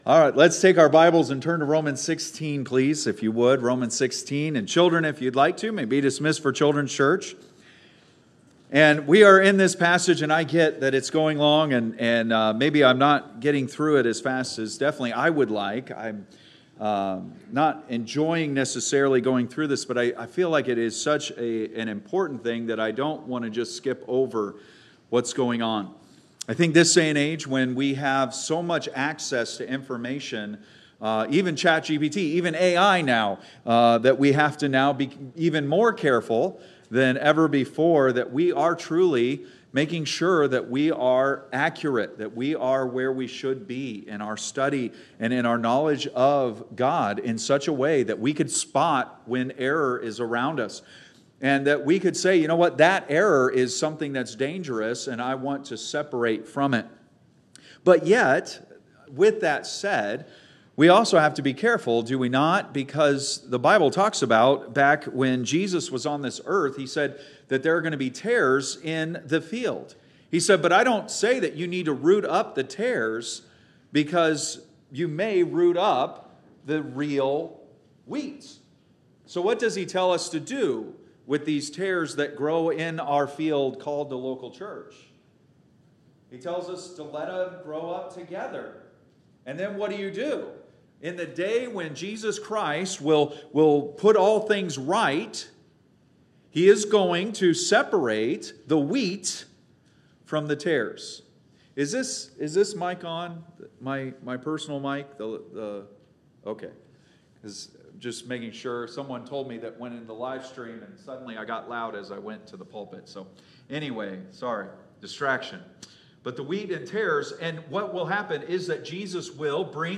Date: February 8, 2026 (Sunday Morning)